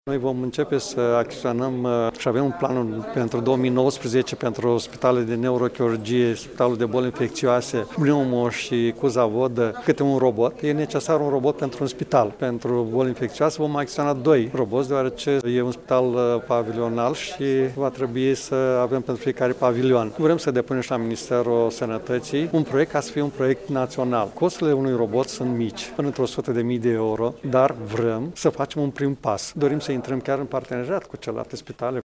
Un astfel de robot costă aproximativ 100 de mii de euro, a mai spus Maricel Popa: